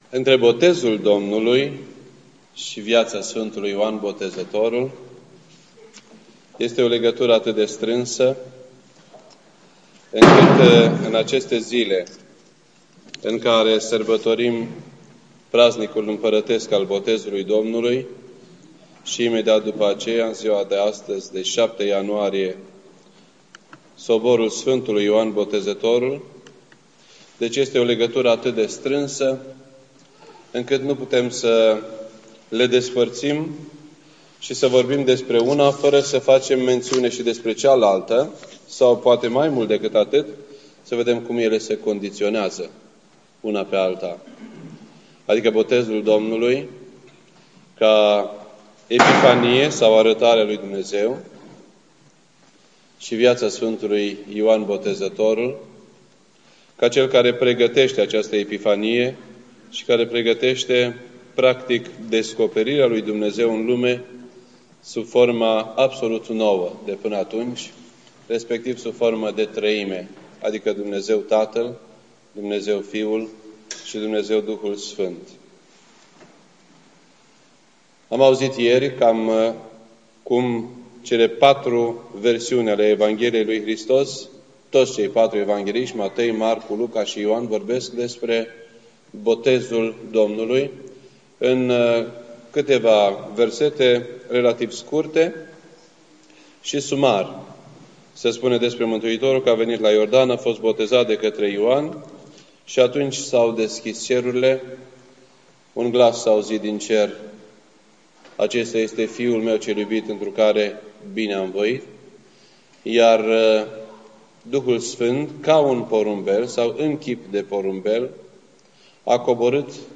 This entry was posted on Saturday, January 7th, 2012 at 8:54 PM and is filed under Predici ortodoxe in format audio.